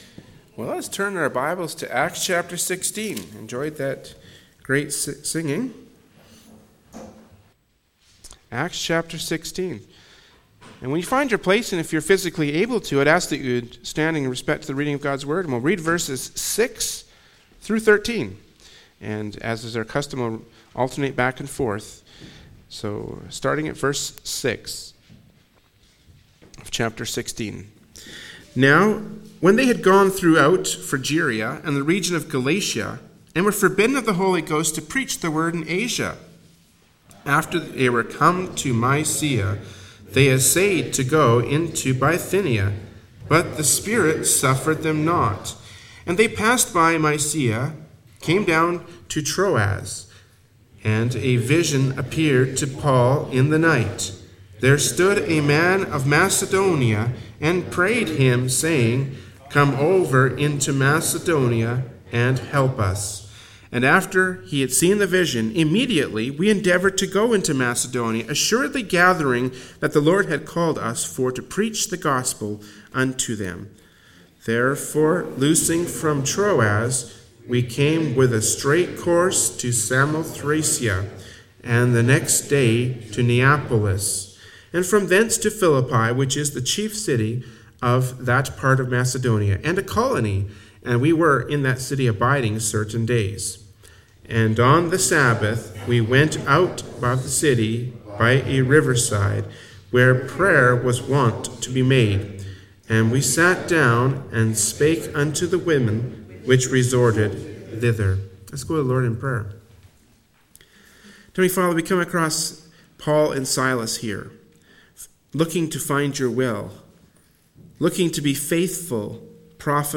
Passage: Acts 16:6-13 Service Type: Sunday Morning Worship Service